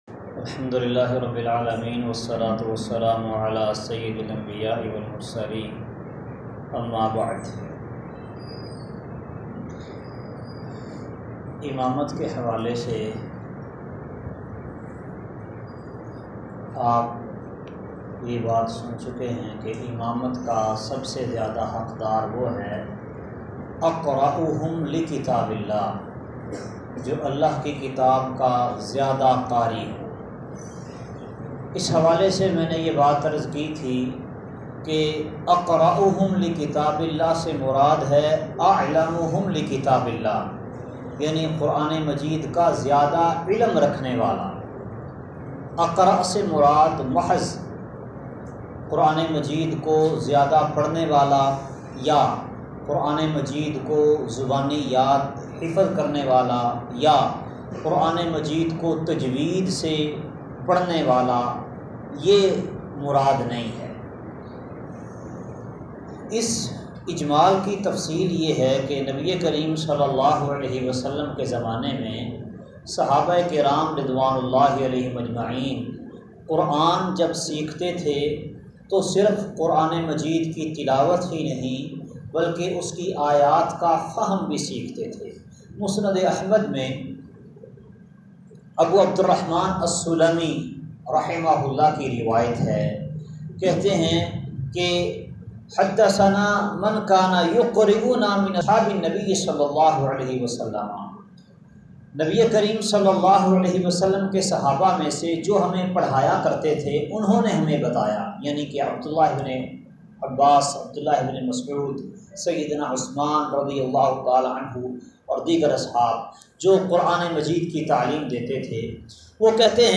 بڑا عالم ہی بڑا قاری ہے درس کا خلاصہ نبیﷺ کےزمانے میں جو قرآن کا جتنا بڑا قاری ہوتا تھا، اتنا بڑا عالم بھی ہوتا تھا اوراس کے احکام اور عمل بھی جانتا ہوتا تھا ۔